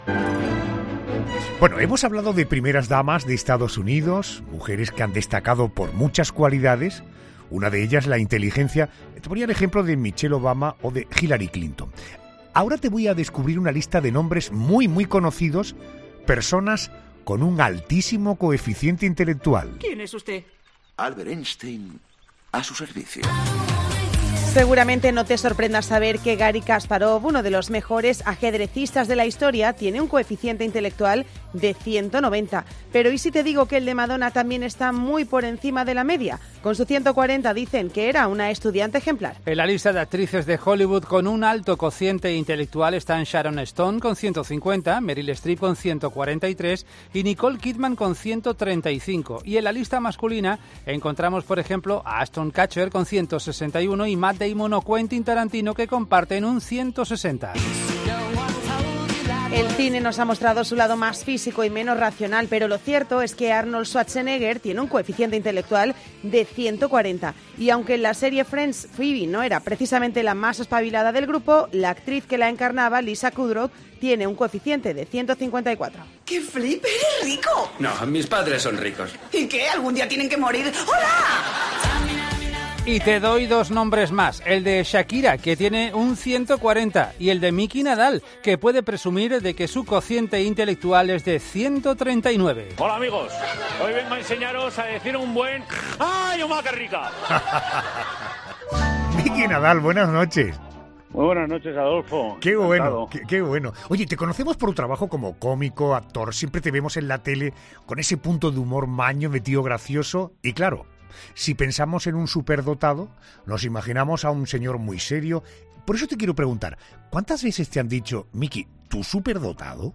El humorista Miki Nadal ha pasado por los micrófonos de La Noche de Adolfo Arjona y ha reconocido que nadie piensa que pueda llegar a ser un “superdotado”: “Me dicen: ¿Cómo va a ser superdotado este del Omá qué rica?”.